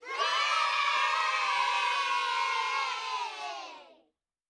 SFX_cheering.wav